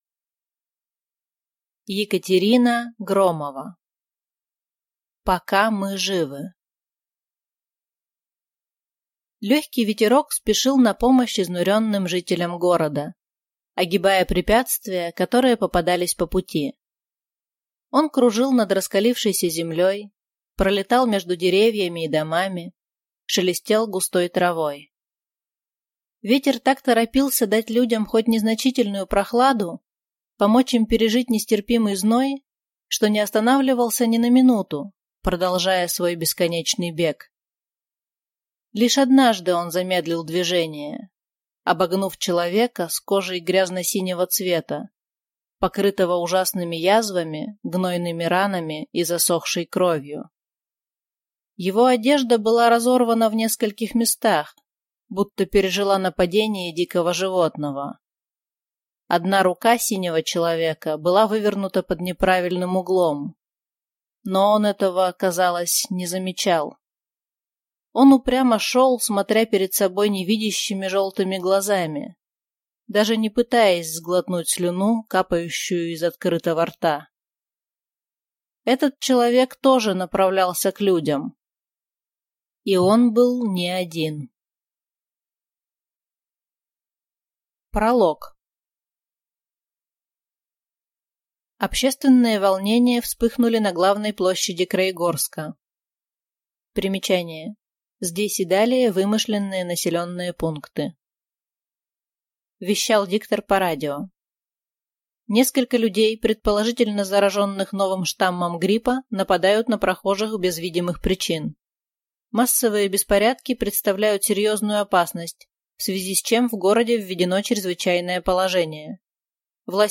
Аудиокнига Пока мы живы | Библиотека аудиокниг